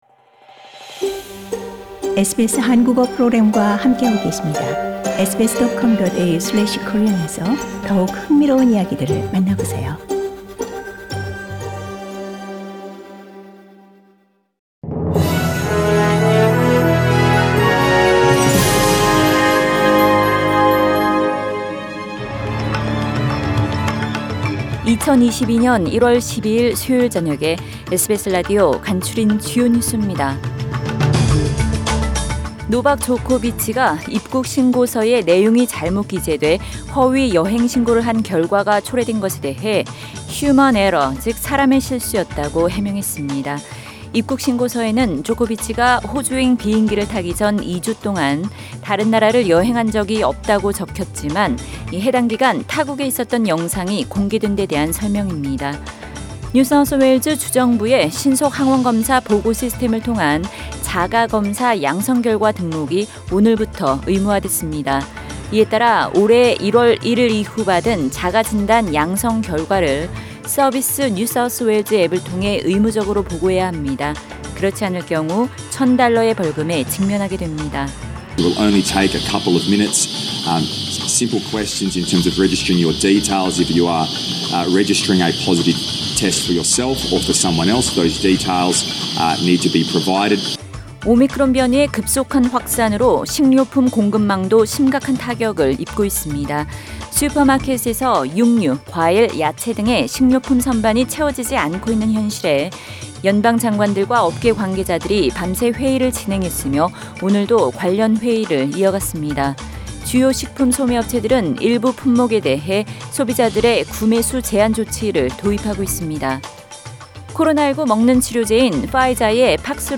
2022년 1월 12일 수요일 저녁의 SBS 뉴스 아우트라인입니다.